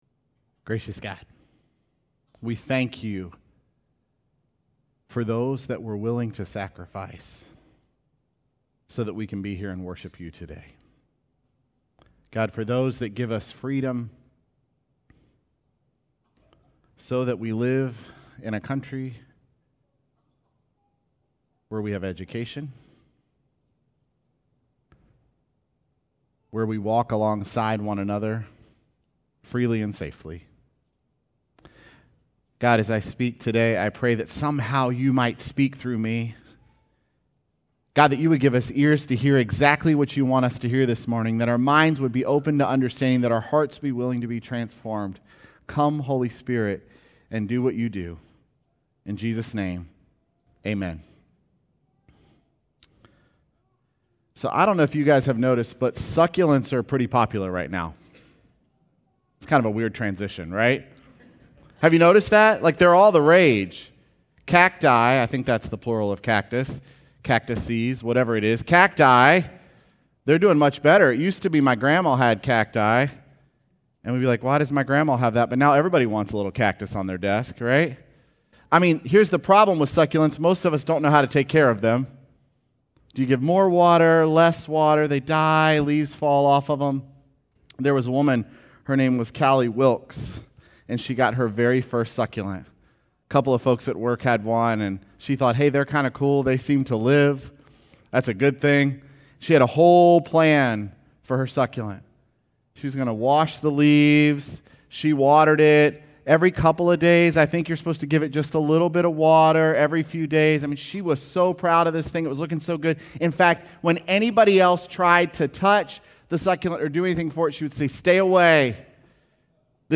Sermons
May26SermonPodcast.mp3